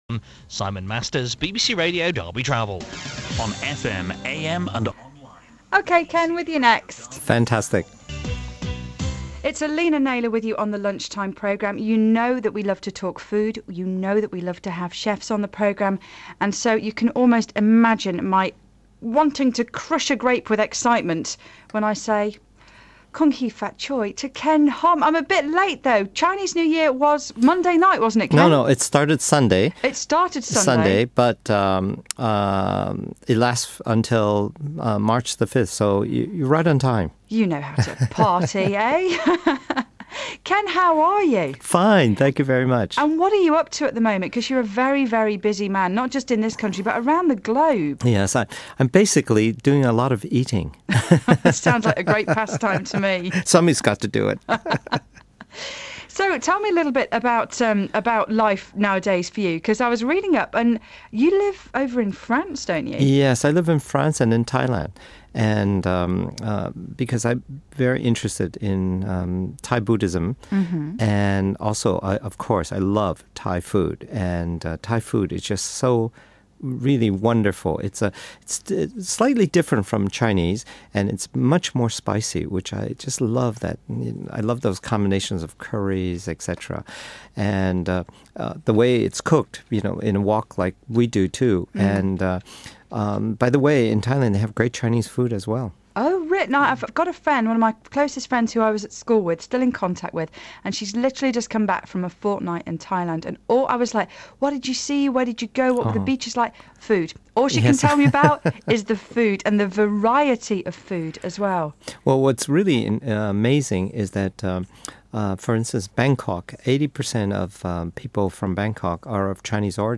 Ken Hom talks to BBC Derby about Chinese New Year.